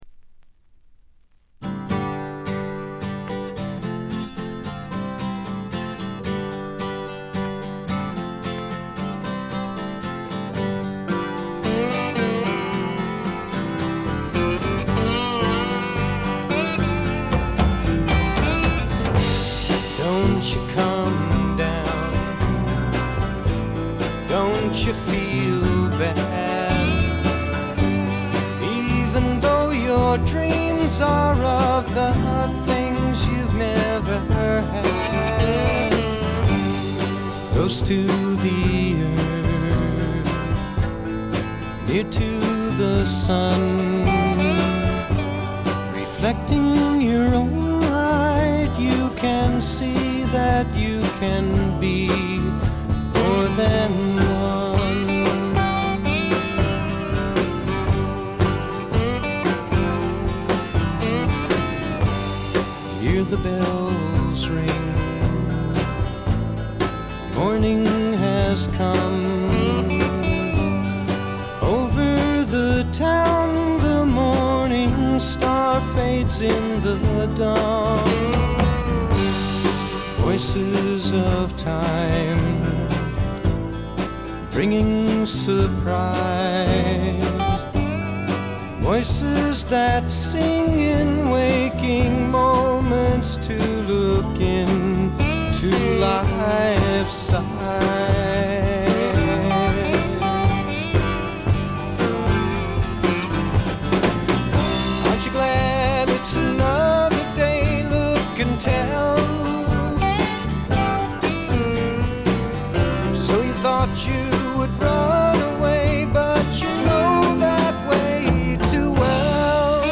ほとんどノイズ感無し。
試聴曲は現品からの取り込み音源です。
Recorded at The Village Recorder